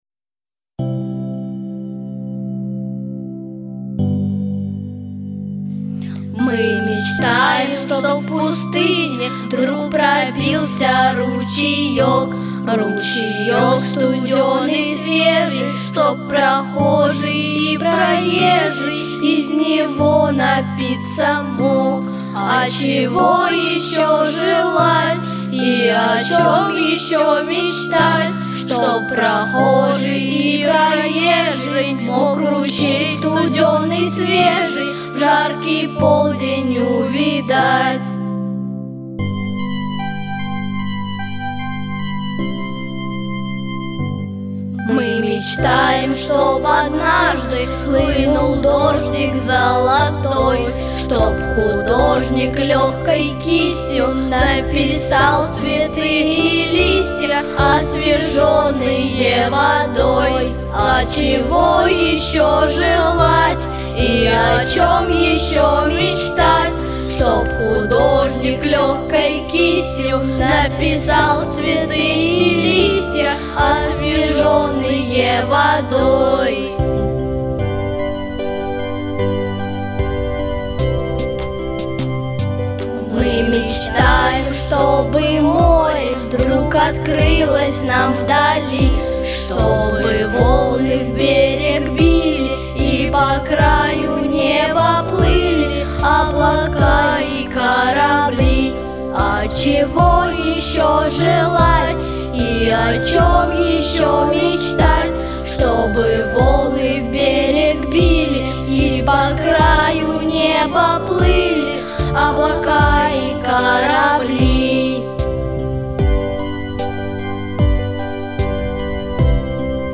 II Театральный Фестиваль начальной школы